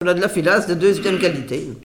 Enquête Arexcpo en Vendée
Catégorie Locution